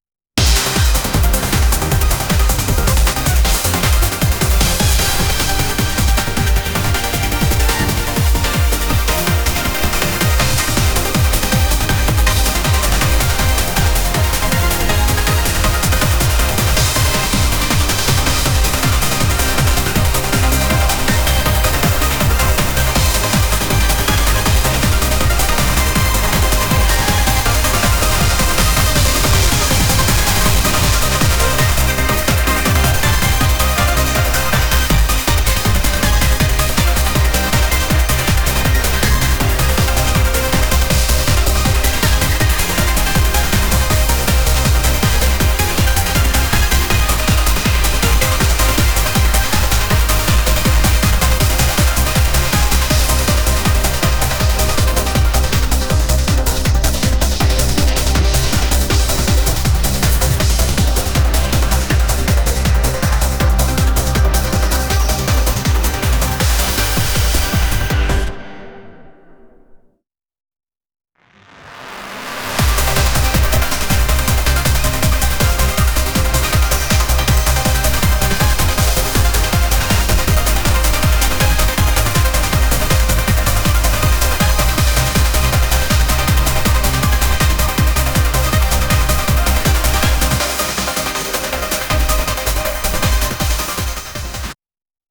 music
fight_4.wav